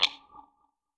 tap.ogg